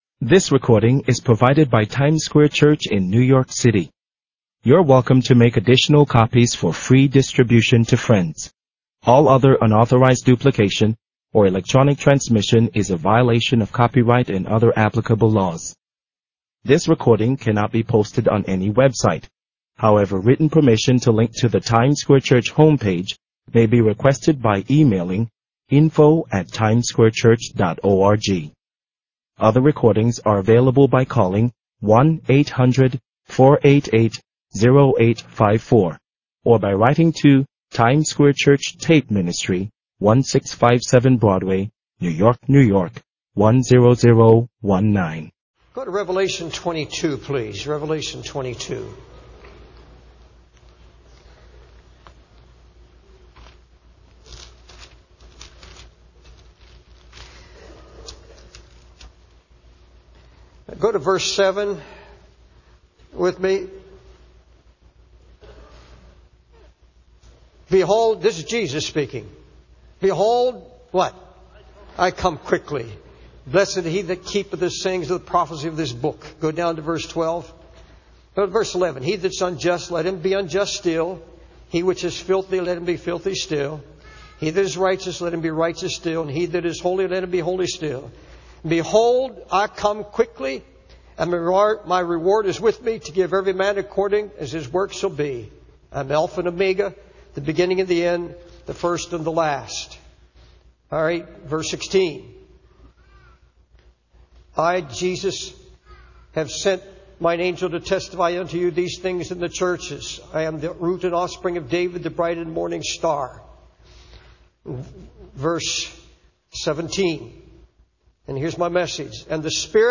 In this sermon, the preacher emphasizes the importance of being ready for the second coming of Jesus Christ. He warns against complacency and urges believers to be vigilant and prepared, comparing it to a homeowner who would have prevented a break-in if he had known the exact time. The preacher also highlights the increasing wickedness in the world and the need for the Holy Spirit to convict sinners.